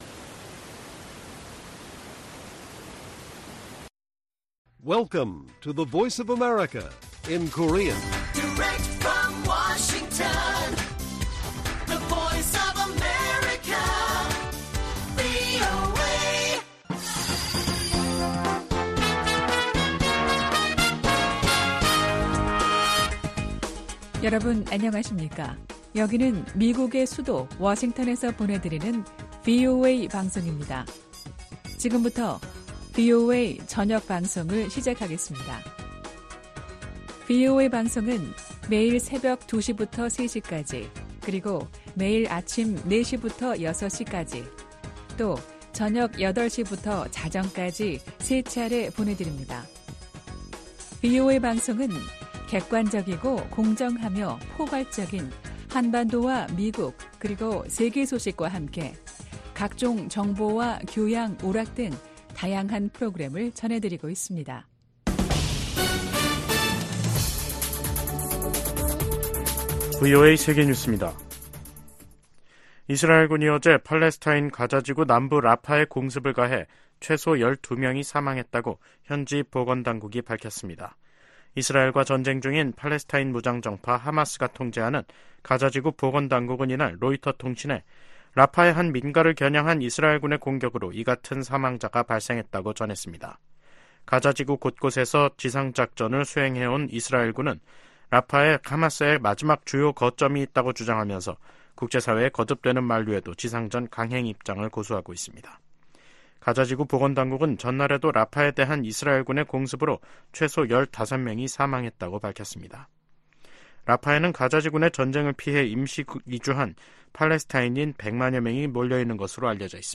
VOA 한국어 간판 뉴스 프로그램 '뉴스 투데이', 2024년 3월 29일 1부 방송입니다. 유엔 안보리 대북 결의 이행을 감시하는 전문가패널의 임기 연장을 위한 결의안 채택이 러시아의 거부권 행사로 무산됐습니다. 이에 미국과 한국·일본 등은 러시아를 강하게 비판했습니다.